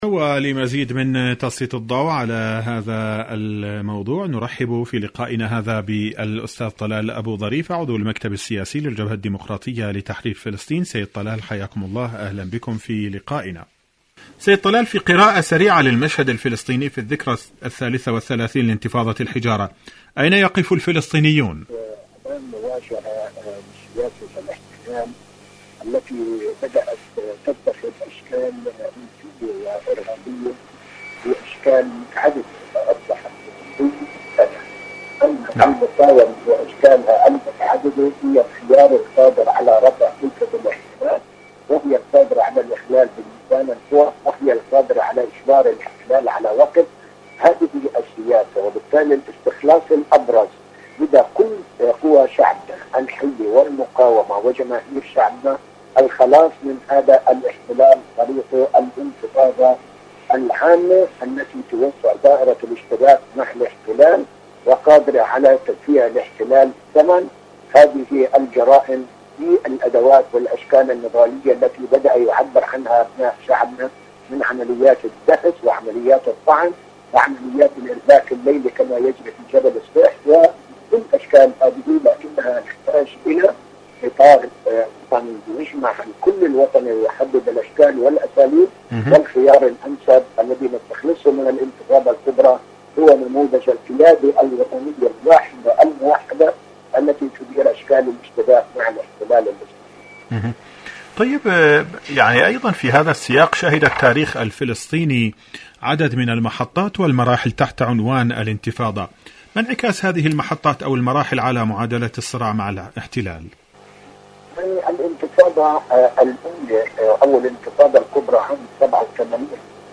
مقابلات إذاعية برنامج فلسطين اليوم